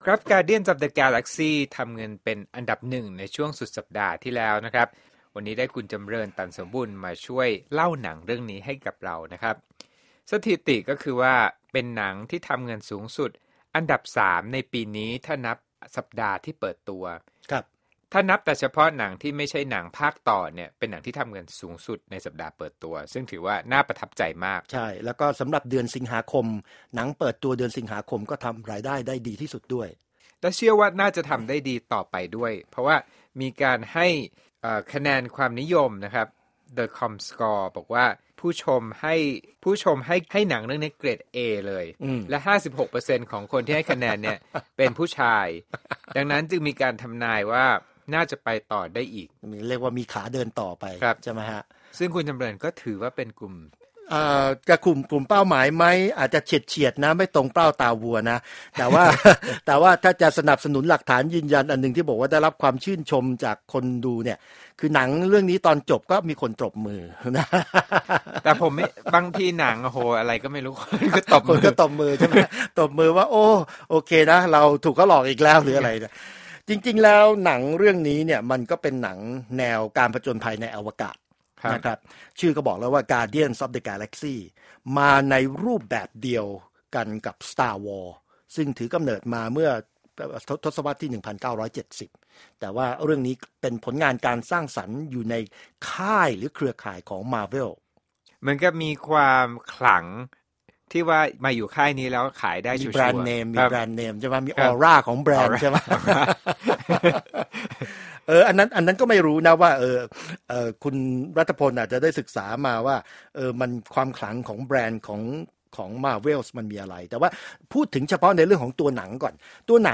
Movie Review:Guardians of Galaxy